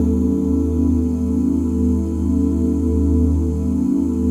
OOHD#FLAT5.wav